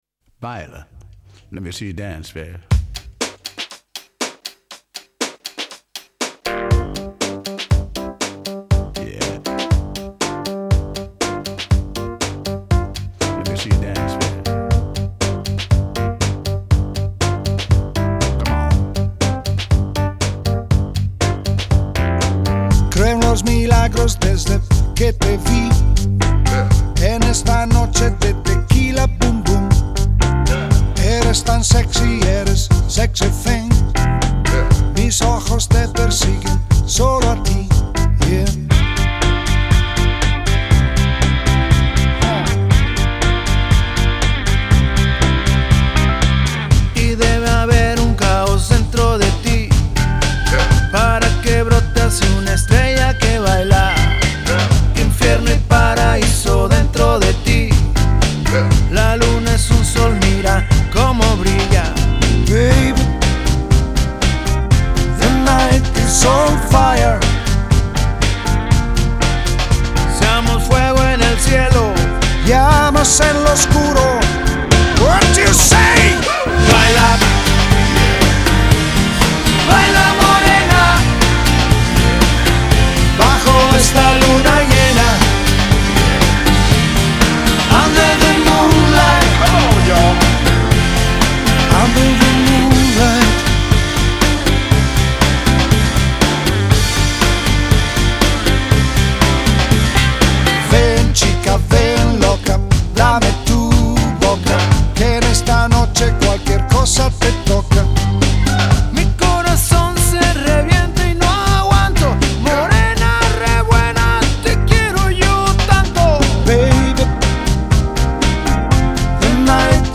Старая итальянская школа, тоже зажигательная.